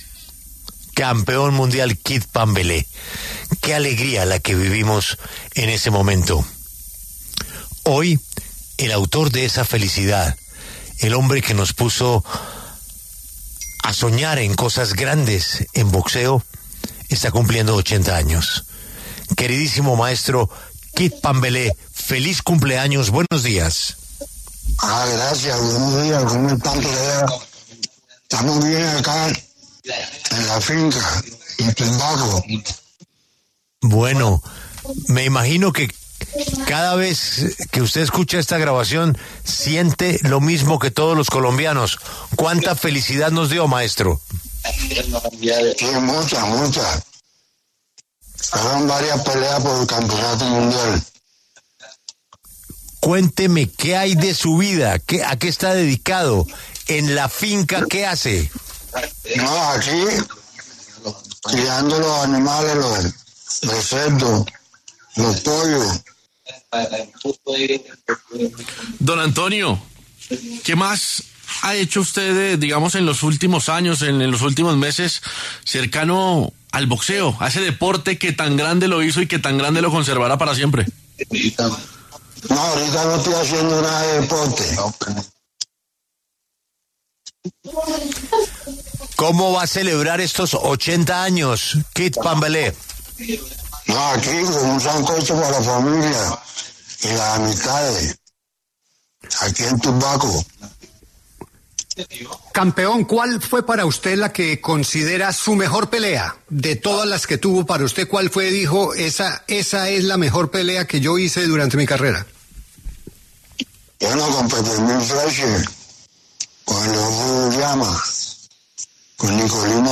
Kid Pambelé habló en La W y contó como lleva su vida actual, su trayectoria y su relación actual con el boxeo.